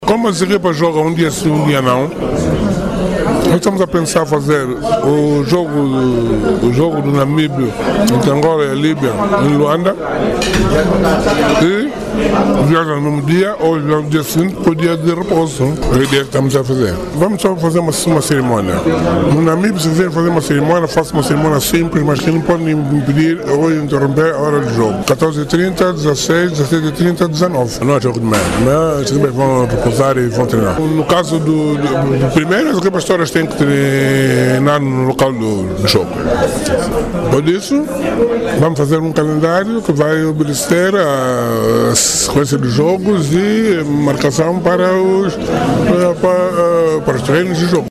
Comissão organizadora analisa Afrobasket masculino em conferência de imprensa